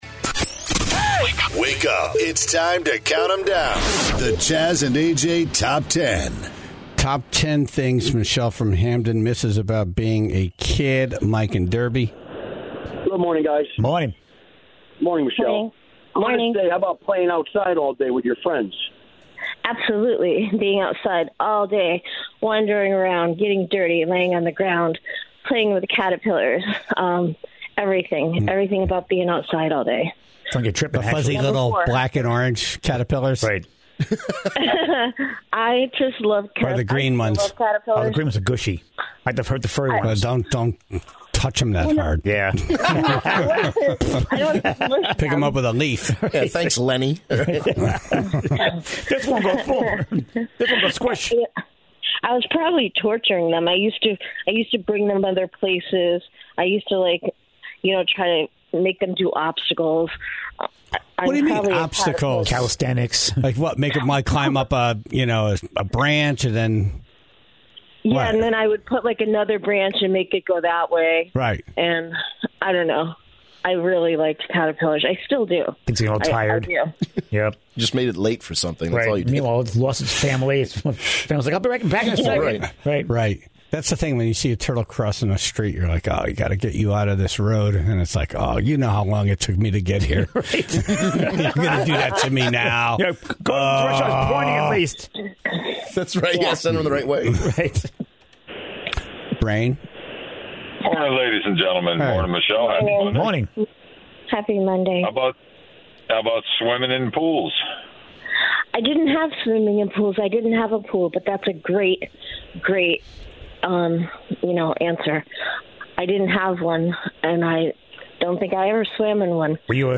Naugatuck Mayor Pete Hess was on the phone to help out.